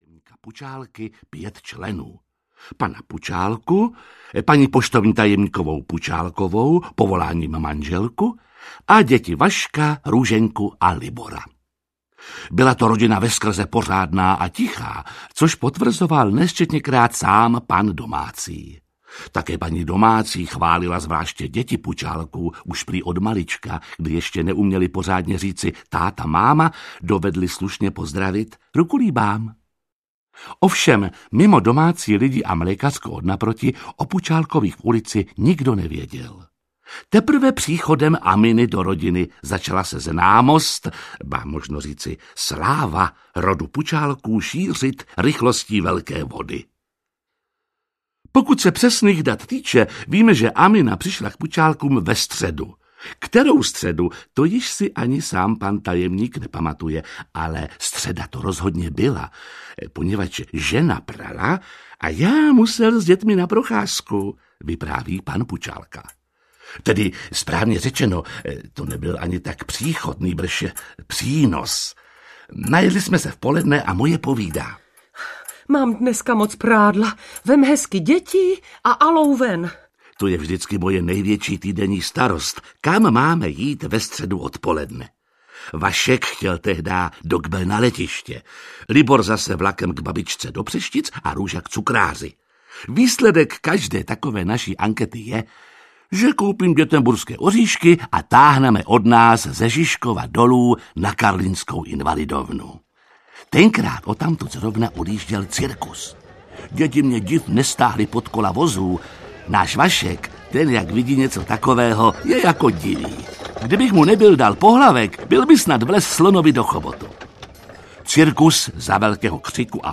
Pučálkovic Amina audiokniha
Ukázka z knihy